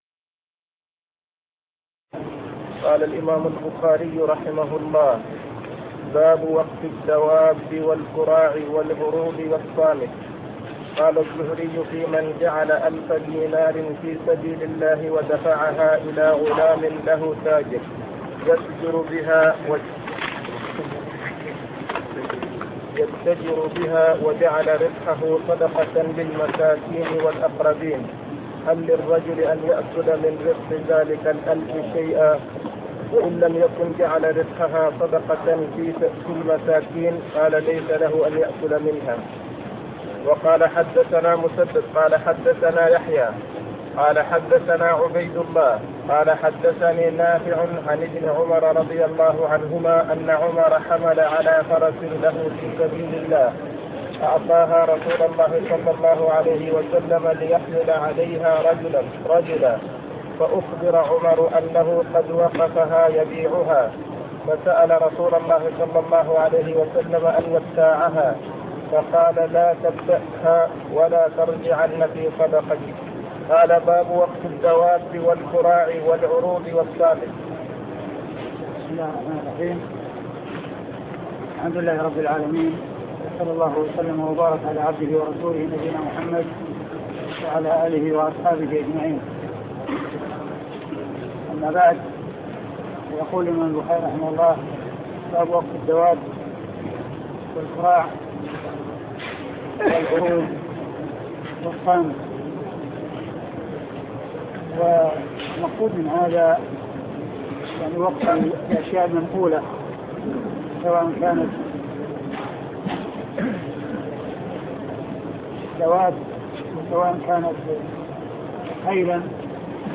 صحيح البخاري شرح الشيخ عبد المحسن بن حمد العباد الدرس 312